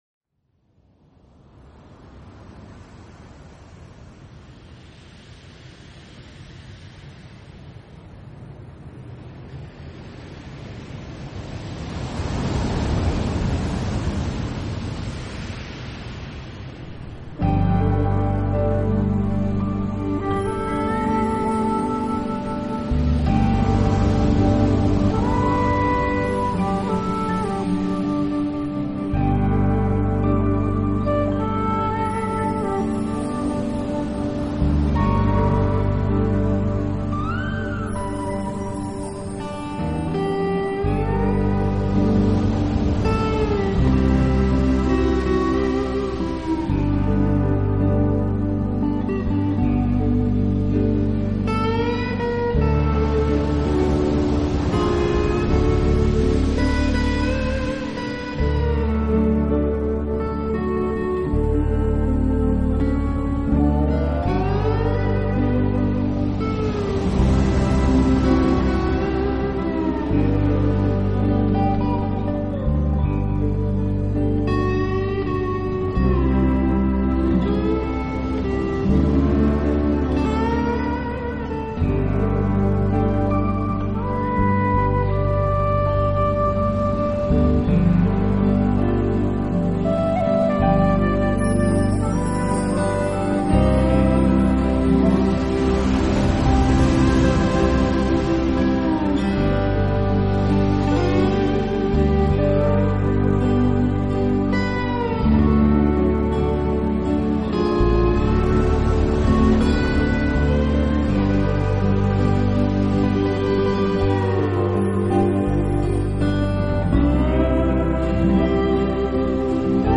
【新世纪纯音乐】
(再见).夏威夷吉他的音色十分温柔惬意，让整张专辑十分适合懒洋洋的你午后在海